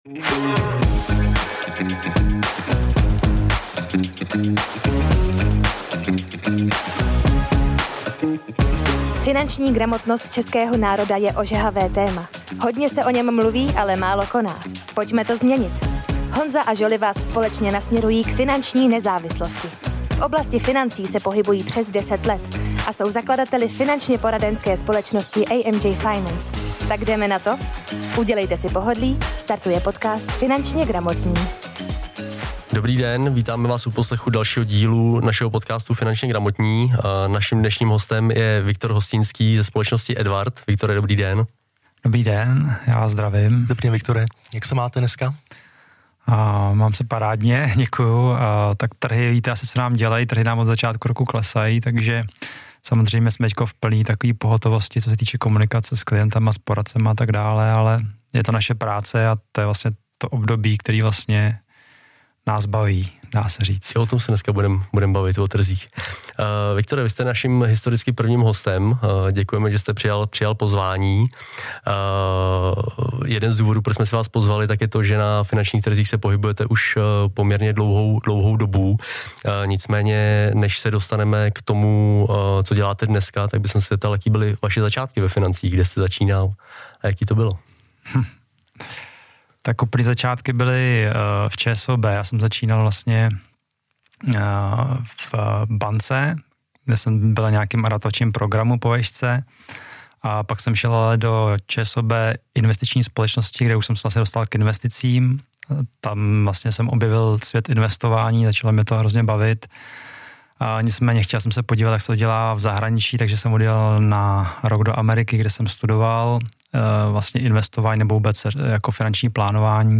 FINANČNĚ GRAMOTNÍ / Rozhovor